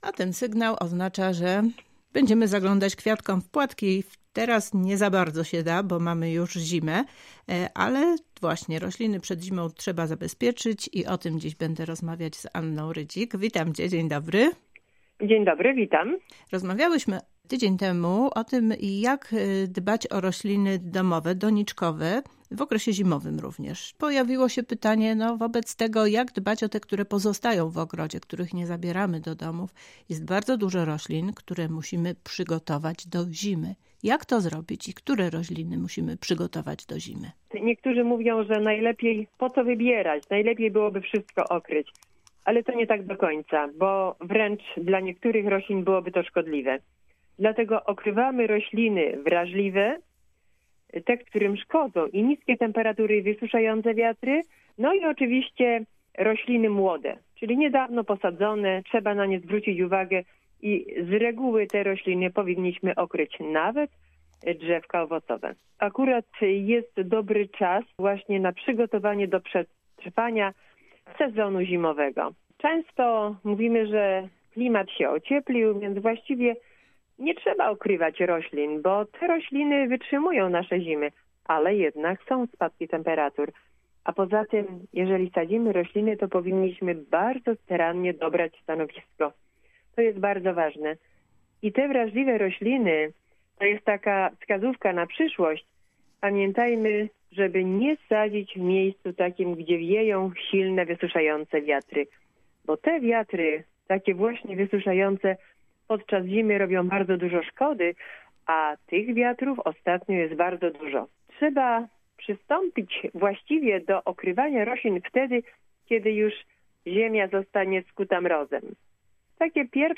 • Audycje • Polskie Radio Rzeszów